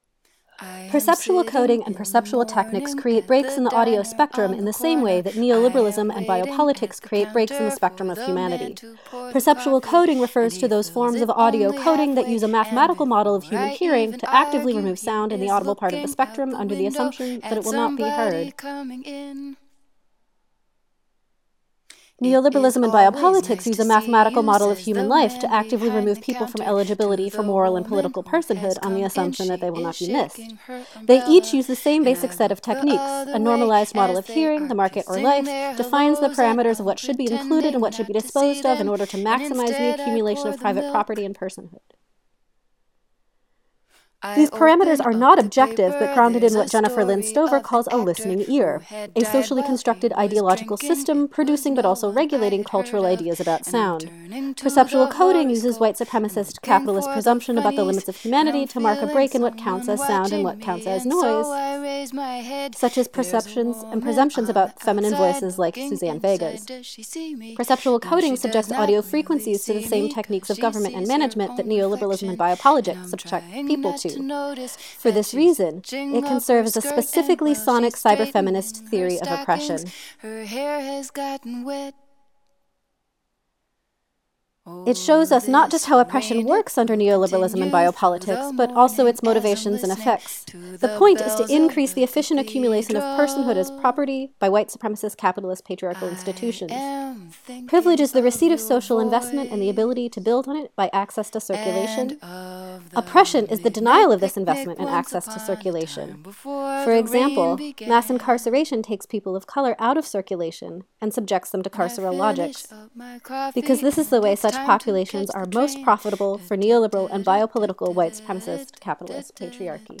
critical karaoke-style sound piece
a cappella